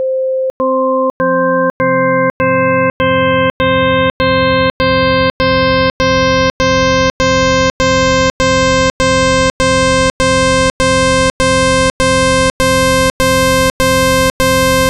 30Charmonies_30percent_high_boost.wav